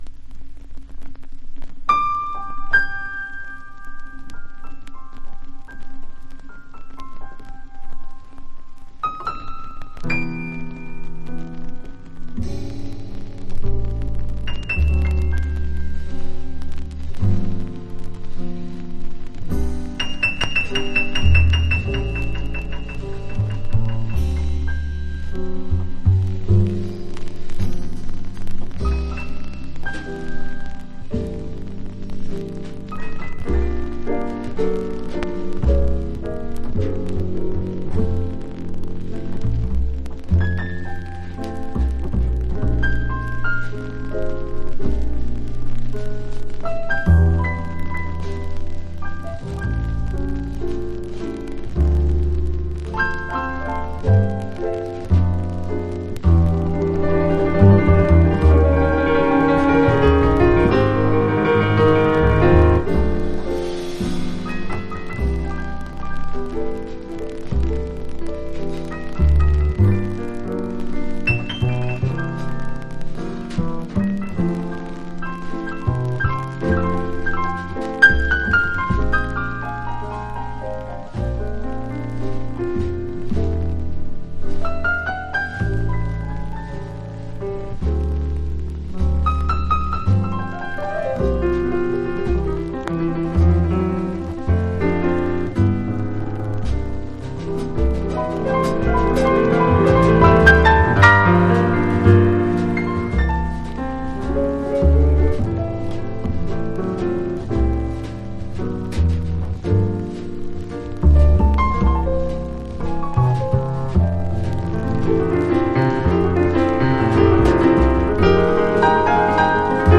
（小傷によりチリ、プチ音ある曲あり）(A-1,2 周回ノイズあり)※曲名をクリ…